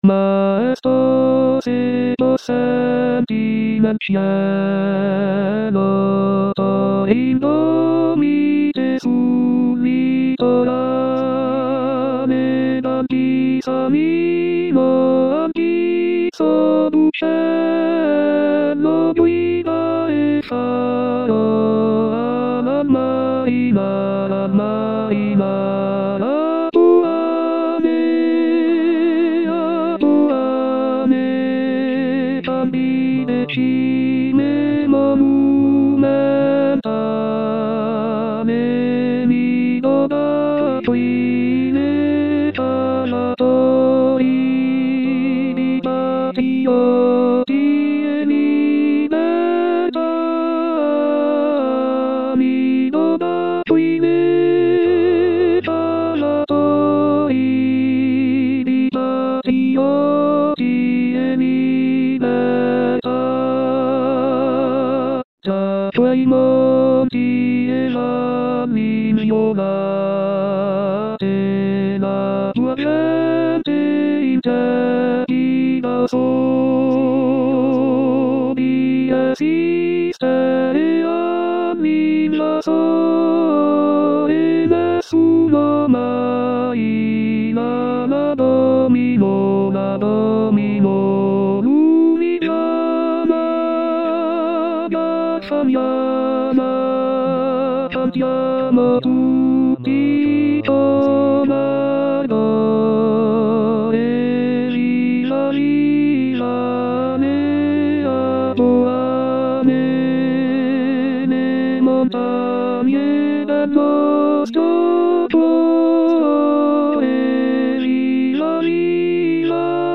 Gruppo Corale FMIR - 30 Giugno 2011
tenori primi tenori secondi baritoni bassi